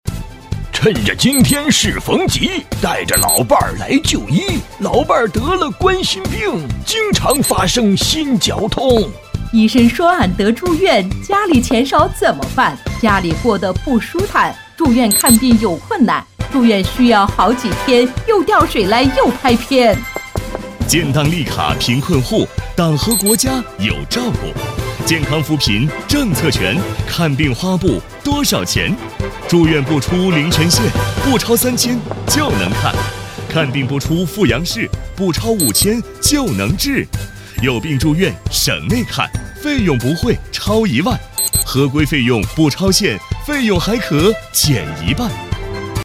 女12老年音
男女混录健康扶贫 成熟 女12老年音
女12 男女混录健康扶贫.mp3